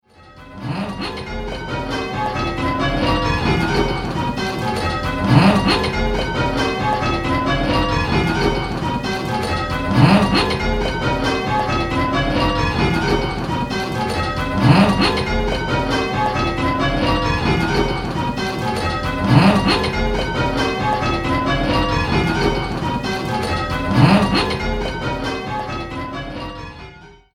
Where's the Treble: 10-Bell - Pebworth Bells